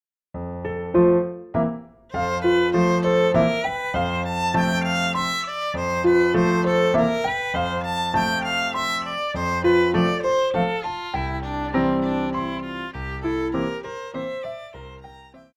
Classical
Piano
Solo with accompaniment